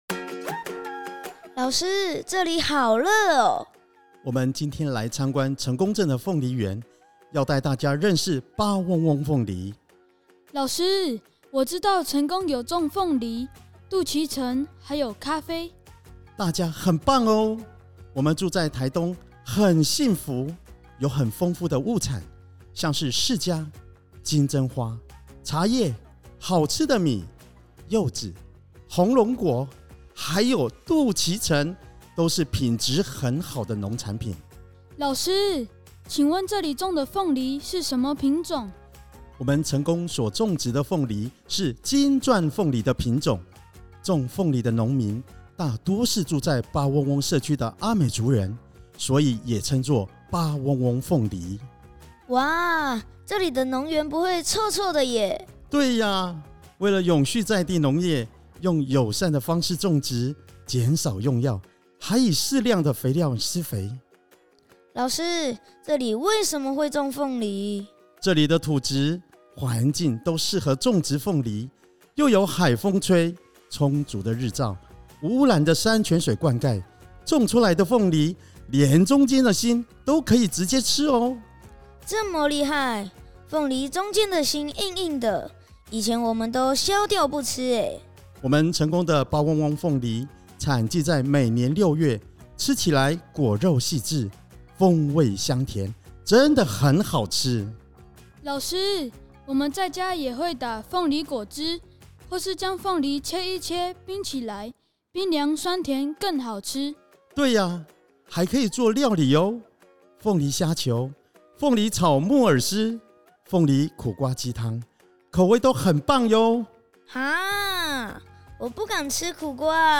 「吹海風的鳳梨~八嗡嗡鳳梨」廣播劇｜食農教育資訊整合平臺